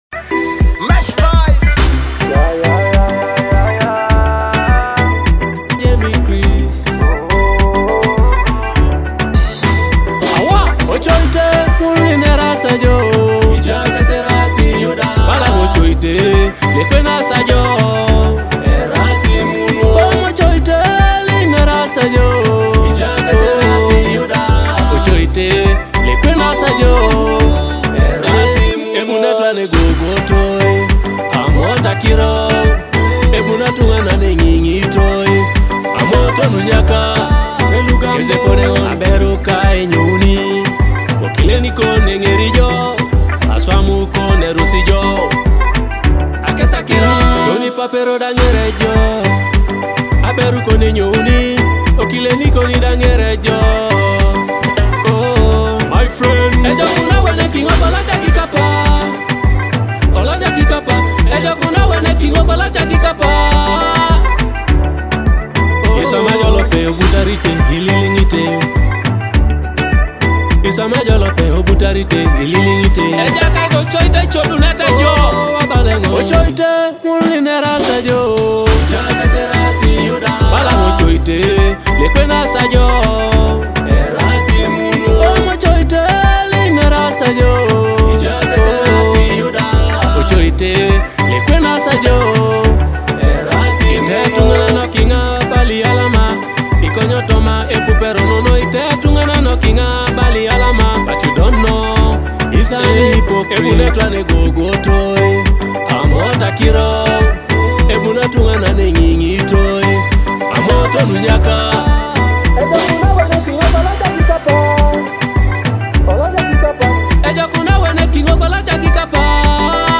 With catchy beats and confident lyrics